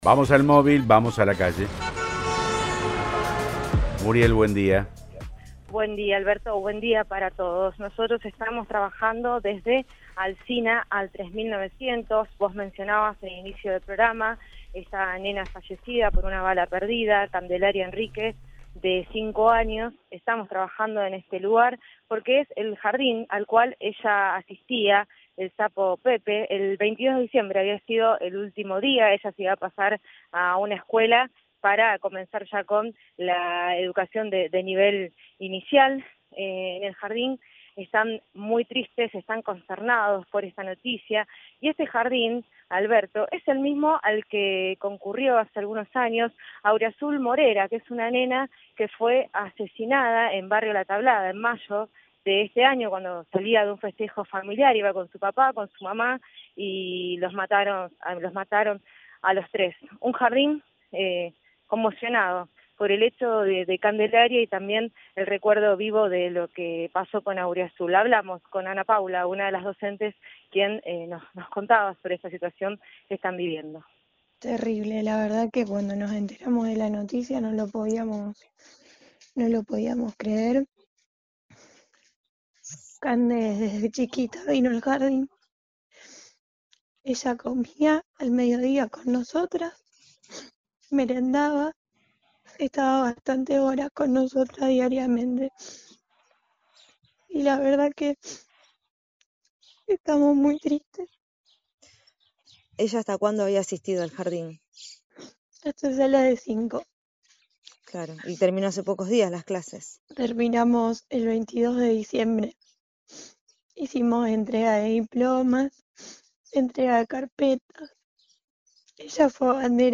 “Vino al jardín hasta la sala de 5. Terminamos el 22 de diciembre. Hicimos entrega de diploma y carpetas. Ella fue abanderada”, recordó entre lágrimas.